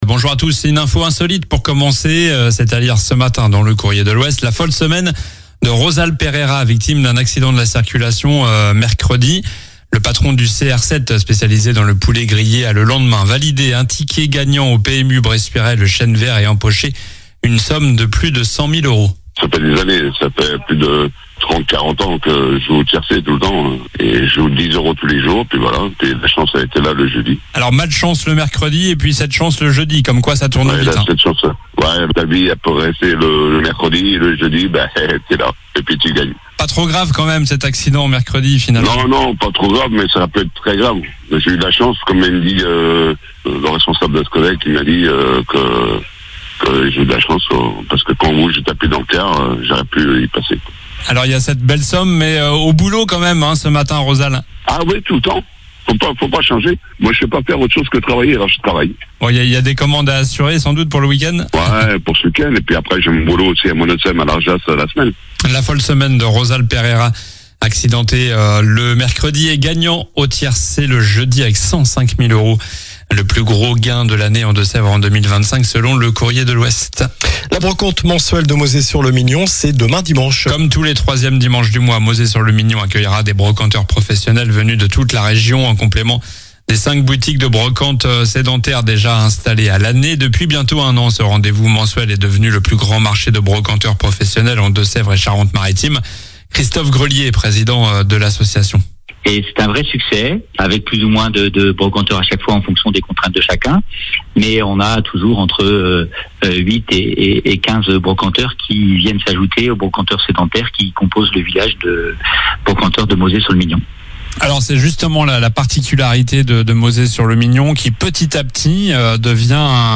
Journal du samedi 19 juillet (matin)